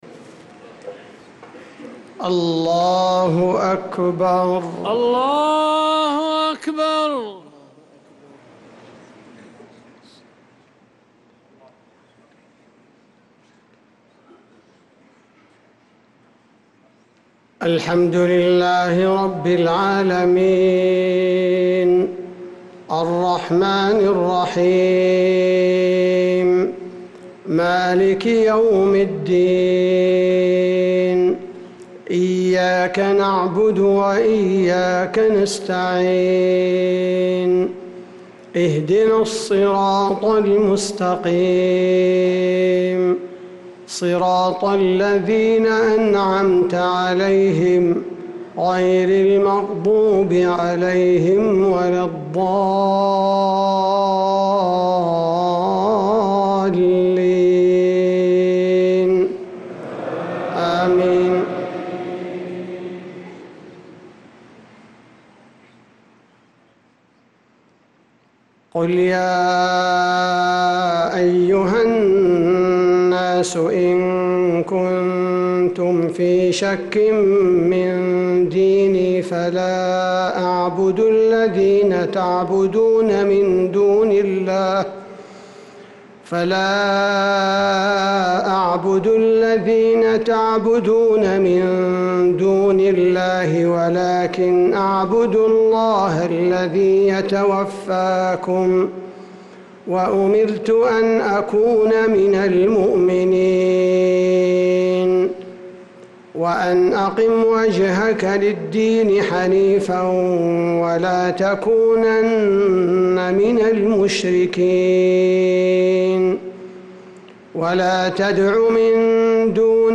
صلاة المغرب للقارئ عبدالباري الثبيتي 21 ربيع الأول 1446 هـ